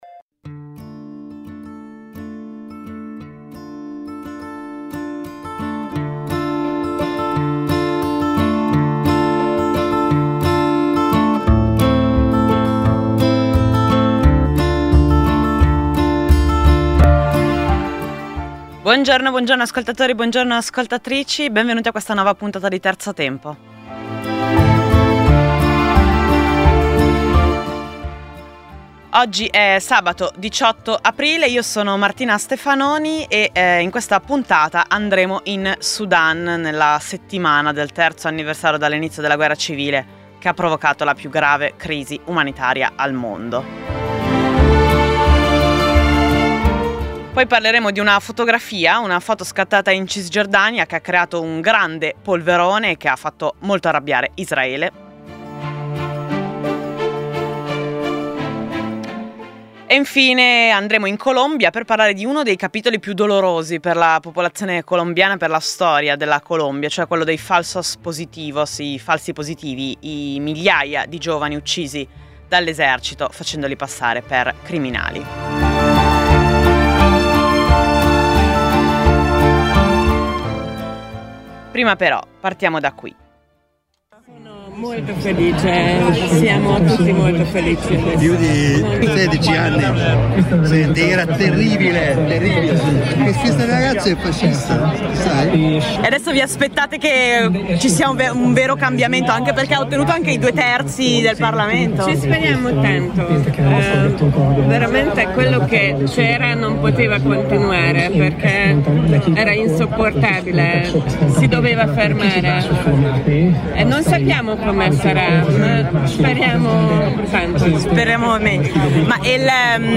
Sarà una mezz’ora più rilassata rispetto all’appuntamento quotidiano, ricca di storie e racconti, ma anche di musica.